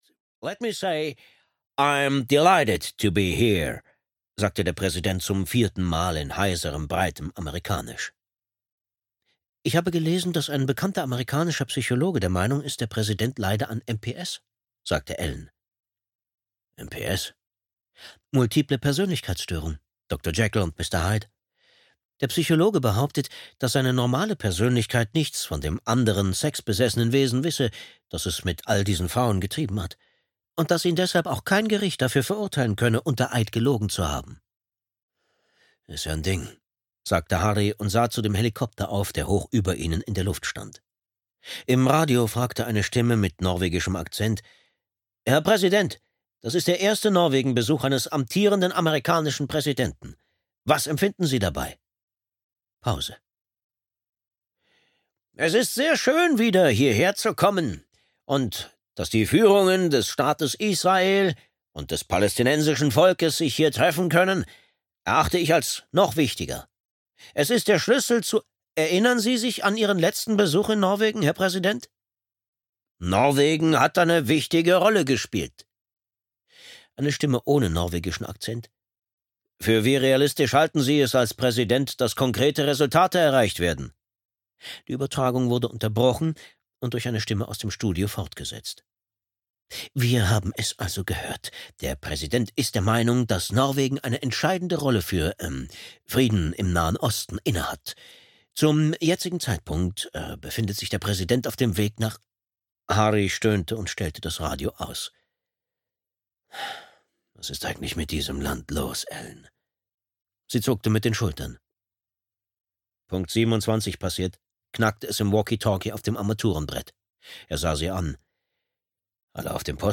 Rotkehlchen (DE) audiokniha
Ukázka z knihy